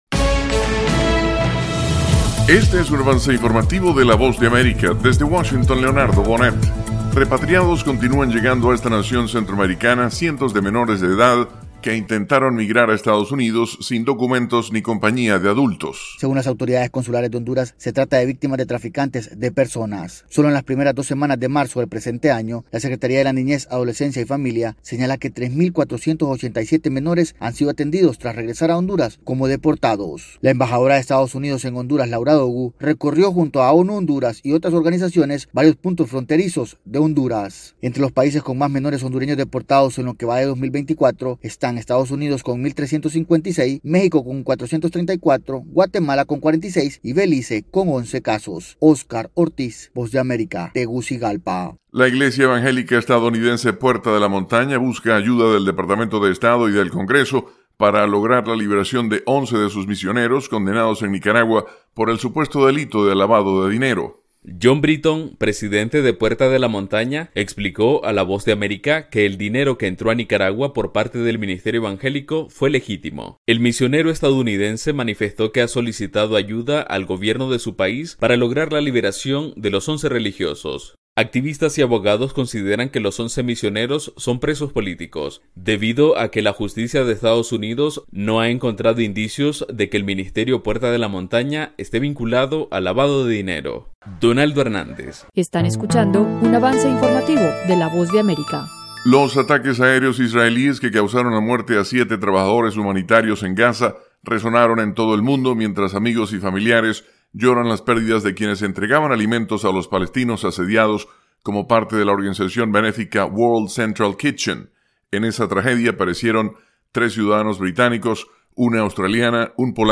El siguiente es un avance informativo presentado por la Voz de América